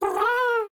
Minecraft Version Minecraft Version 25w18a Latest Release | Latest Snapshot 25w18a / assets / minecraft / sounds / mob / happy_ghast / ambient9.ogg Compare With Compare With Latest Release | Latest Snapshot